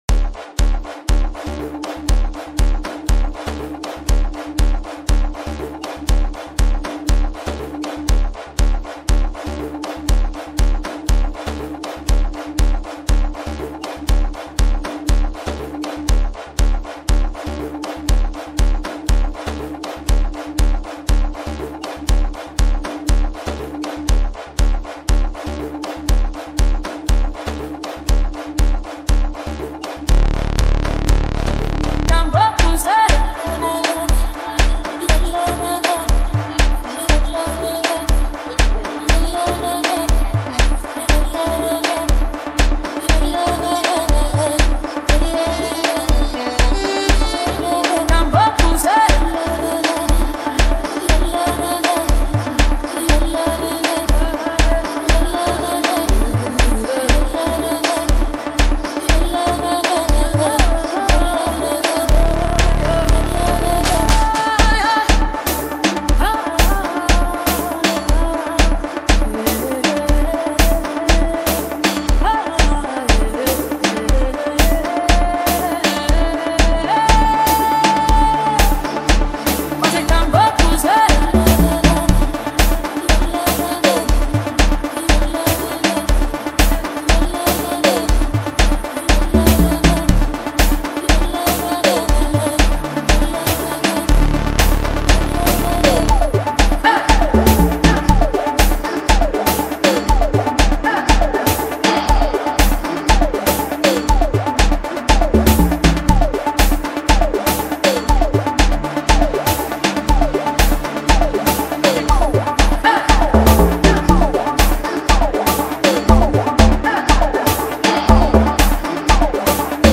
Home » Gqom » DJ Mix » Hip Hop